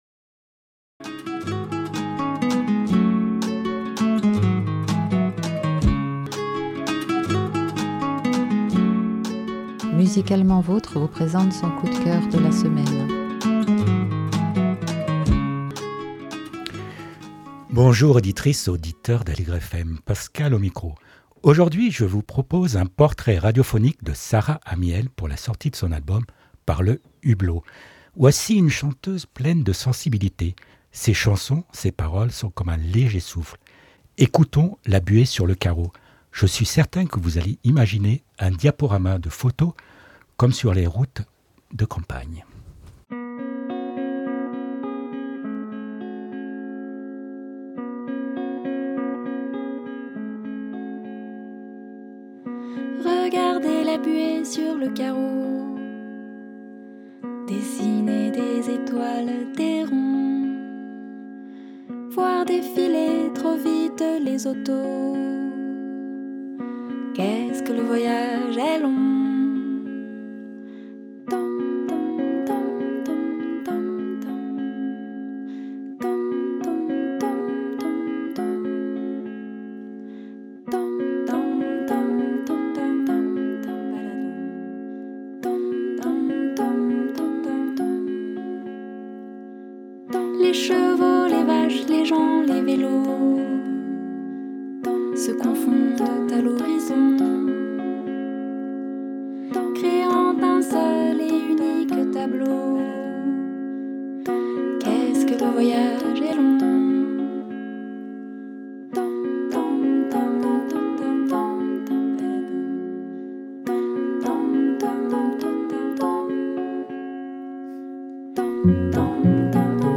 Cette interview vous permettra de mieux connaître cette autrice, compositrice et interprète.